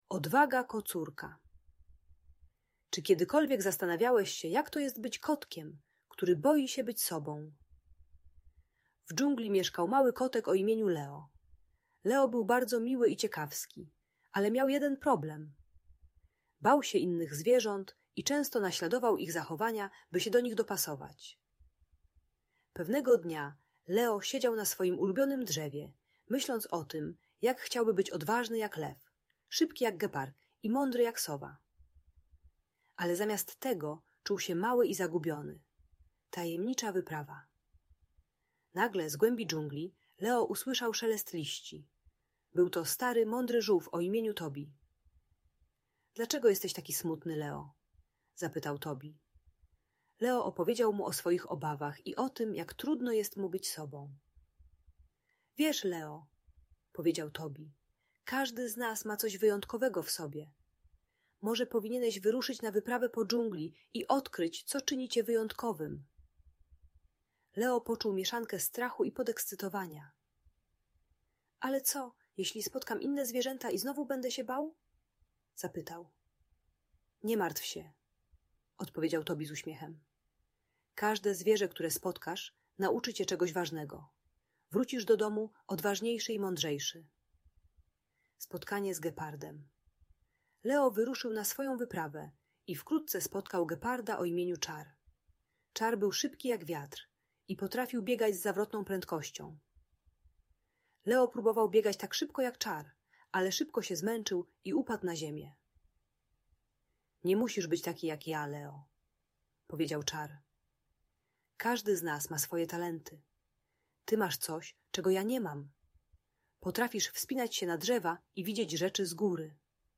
Odwaga Kocurka: Inspirująca historia o odkrywaniu siebie - Audiobajka